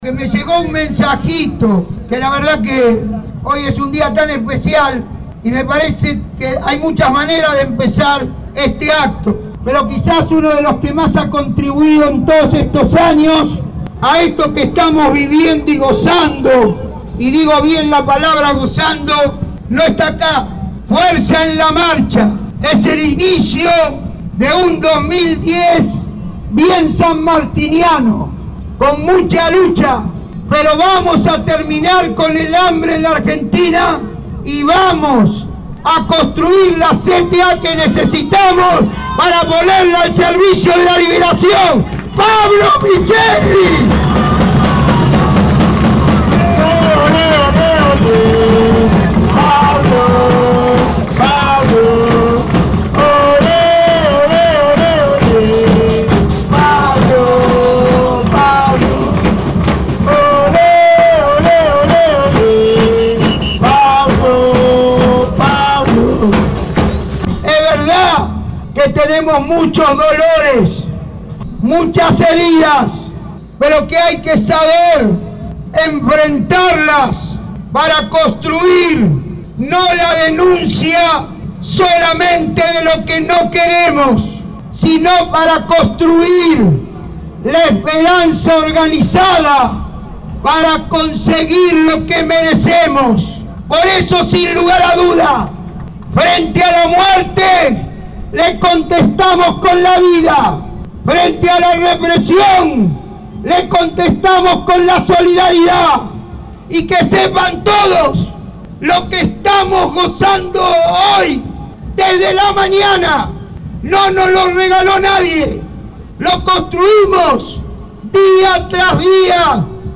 Víctor De Gennaro, Acto frente al Congreso Nacional
Victor_De_Gennaro_Acto_Apertura.mp3